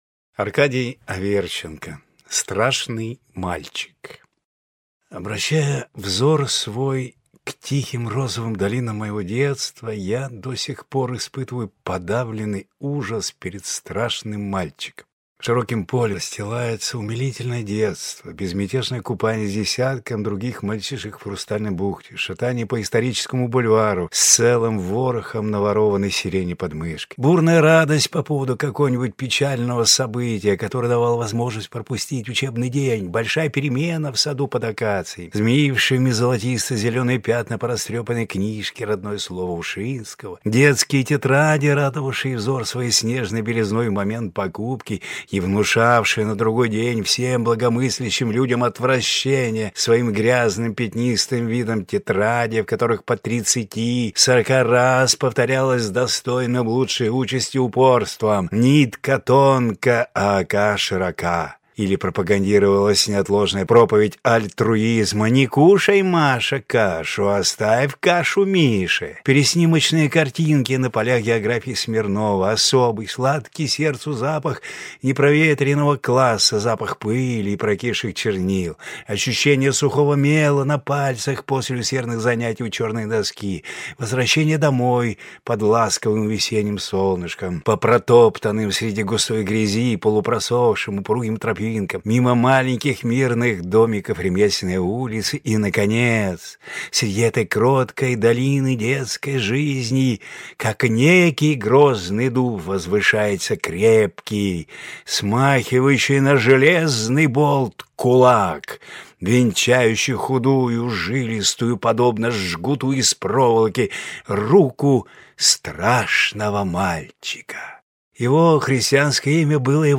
Аудиокнига Страшный Мальчик | Библиотека аудиокниг